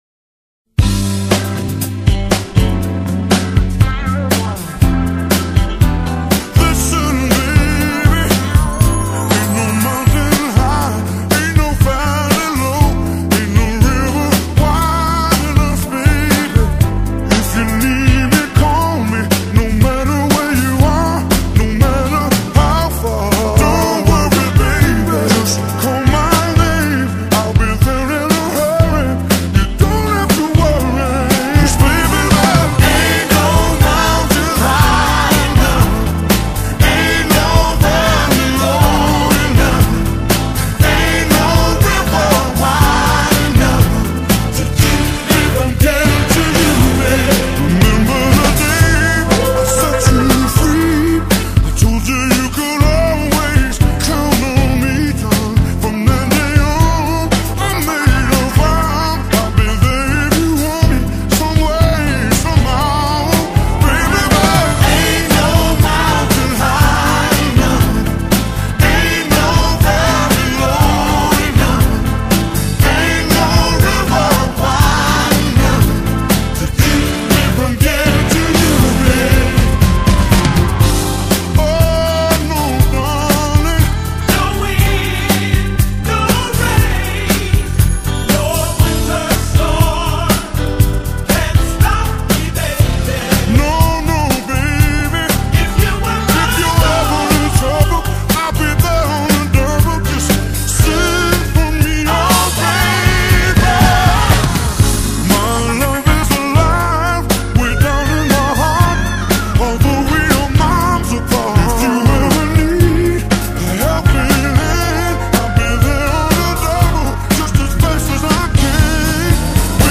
in C